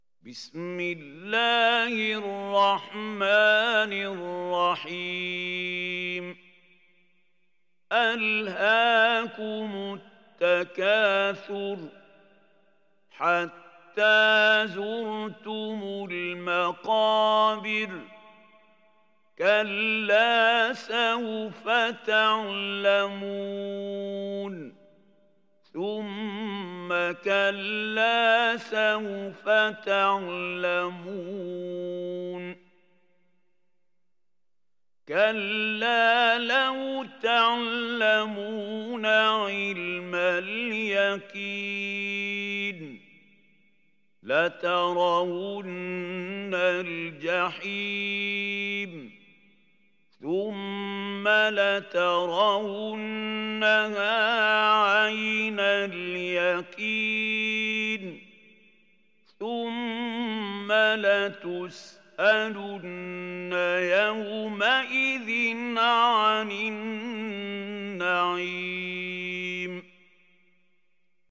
Hafs an Asim